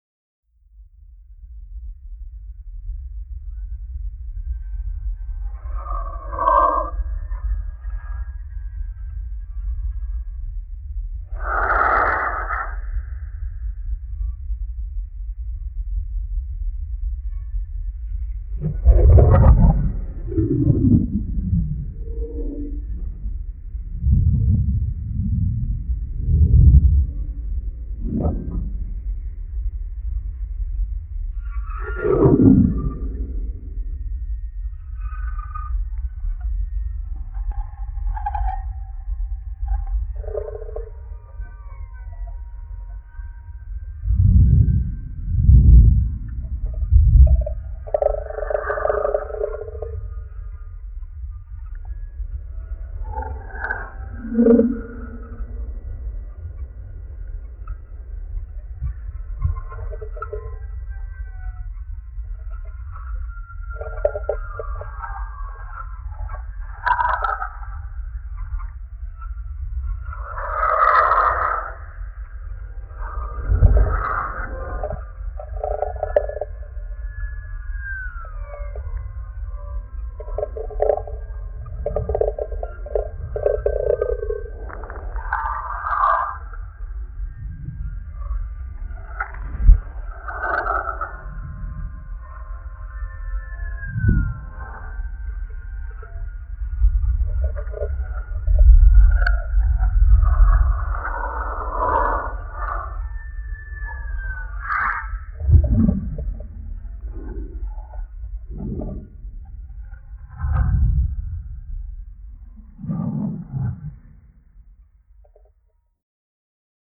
I sped it up by a factor of 60: 24 hours becomes 24 minutes, raising the pitch by almost six octaves and making infrasound audible.
Since ordinary microphones cannot pick up frequencies this low, I constructed infrasonic “macrophones.”
Each consists of a wind-noise reduction array leading to a microbarometer and a data recorder.
3 macrophones placed equilaterally at 100 ft
Playback frequencies: 15–2,400 Hz
Field Recording Series by Gruenrekorder